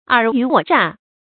注音：ㄦˇ ㄧㄩˊ ㄨㄛˇ ㄓㄚˋ
爾虞我詐的讀法